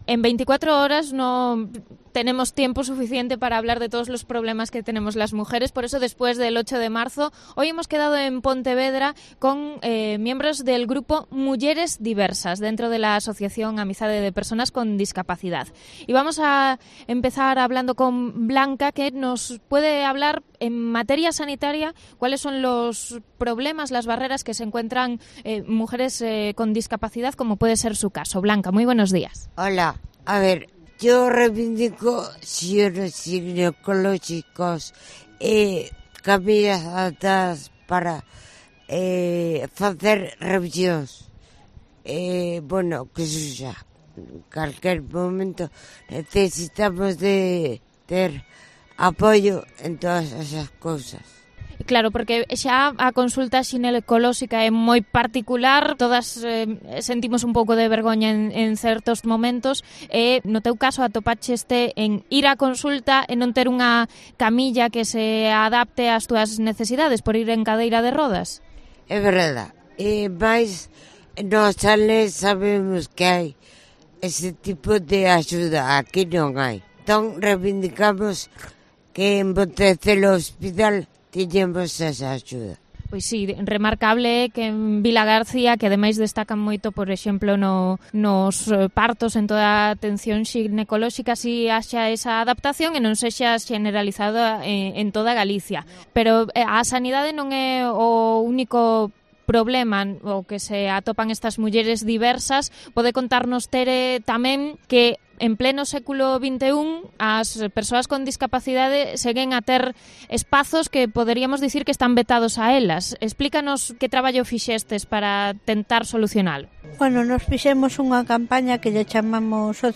Entrevista a tres integrantes de la asociación Amizade